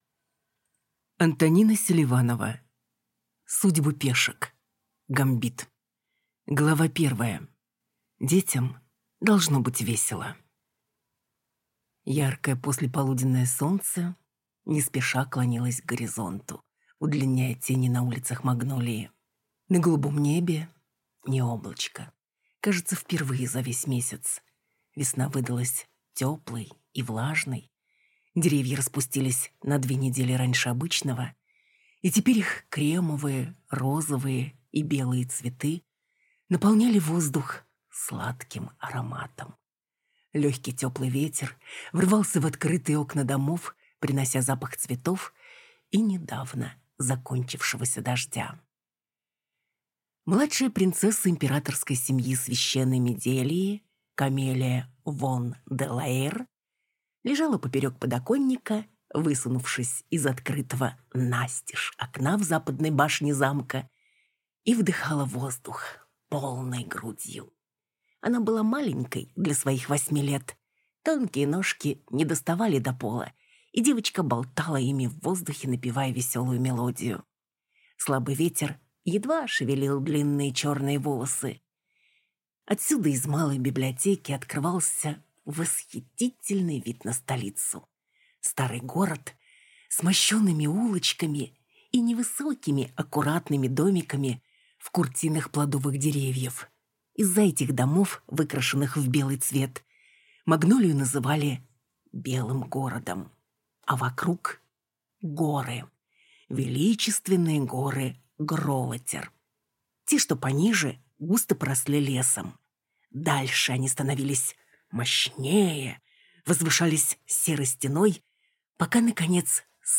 Аудиокнига Судьбы пешек. Гамбит | Библиотека аудиокниг